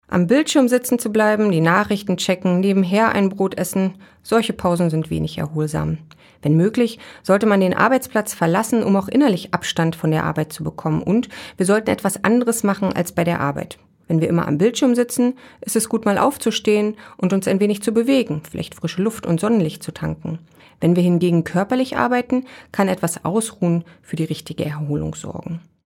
Radio O-Töne